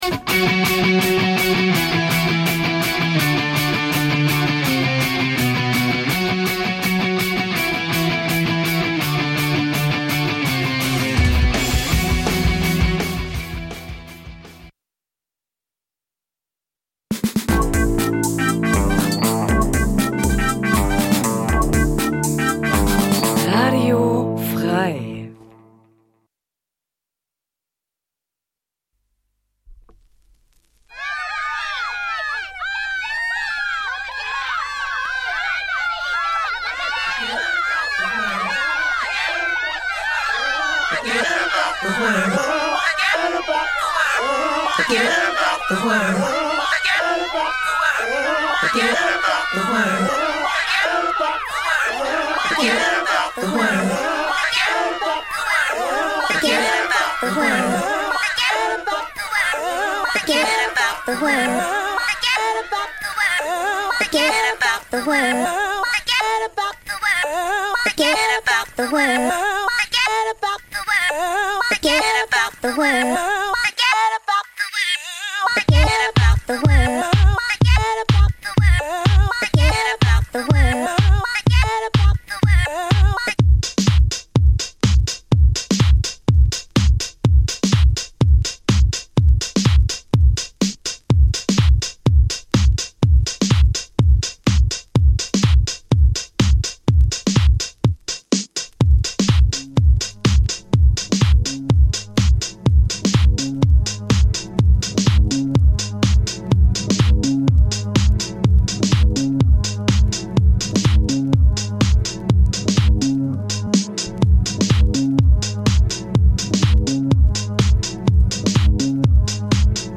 Ein Mix